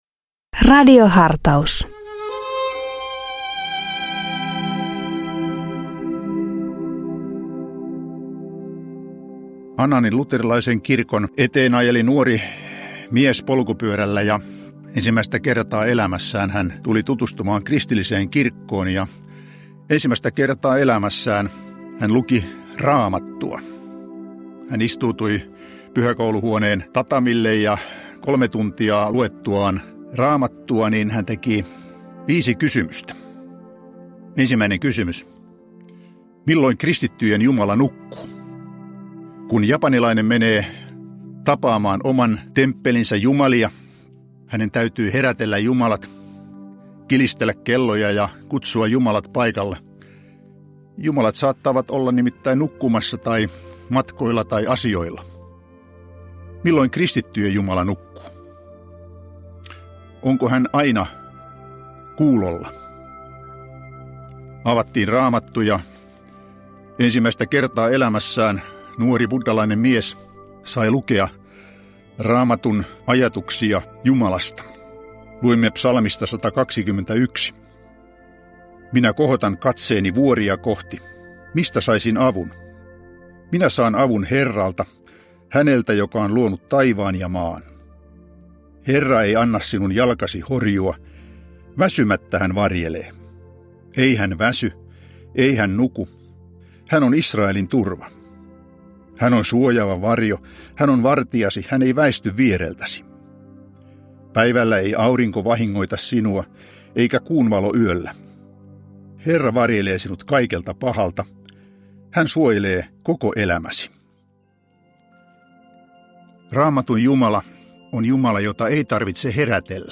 Radio Dei lähettää FM-taajuuksillaan radiohartauden joka arkiaamu kello 7.50. Radiohartaus kuullaan uusintana iltapäivällä kello 17.05.
Radio Dein radiohartauksien pitäjinä kuullaan laajaa kirjoa kirkon työntekijöitä sekä maallikoita, jotka tuntevat radioilmaisun omakseen.